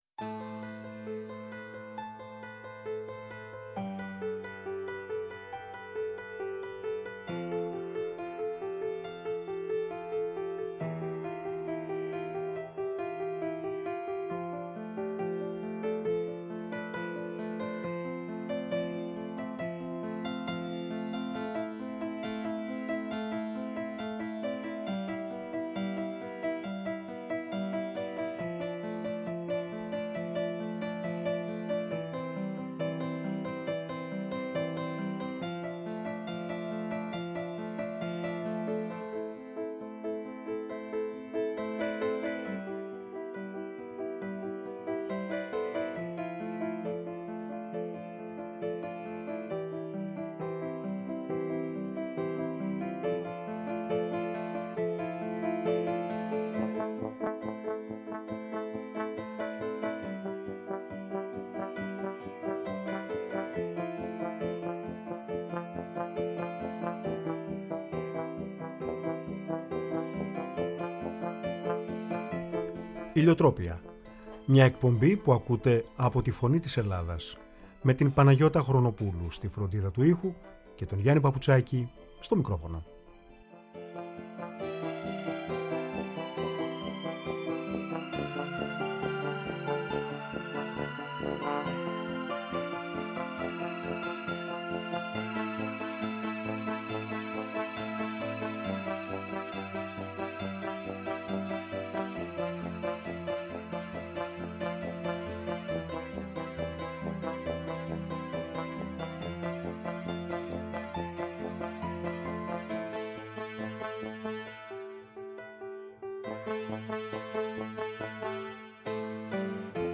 Η εκπομπή διανθίστηκε με μουσικές των σπουδαίων Ρώσων συνθετών Μουσόργκσκι και Τσαϊκόφσκι!
Η ΦΩΝΗ ΤΗΣ ΕΛΛΑΔΑΣ Ηλιοτροπια ΜΟΥΣΙΚΗ Μουσική Πολιτισμός ΣΥΝΕΝΤΕΥΞΕΙΣ Συνεντεύξεις